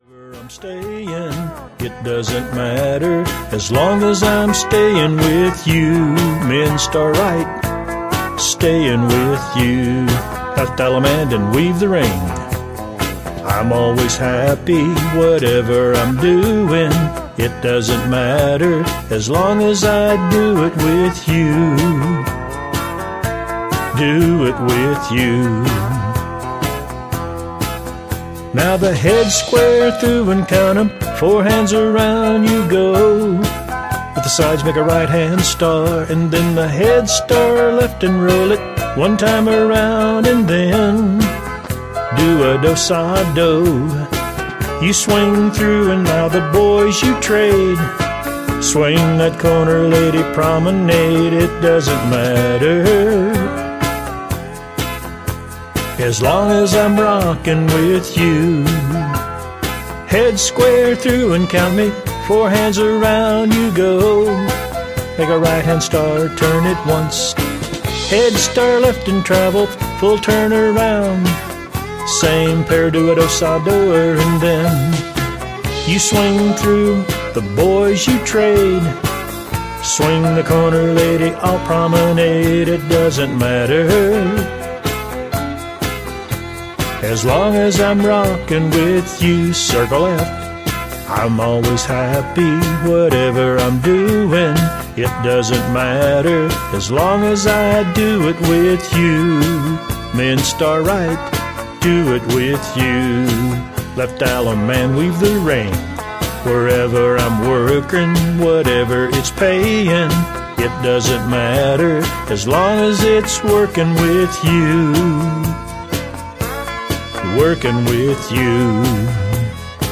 Singing Calls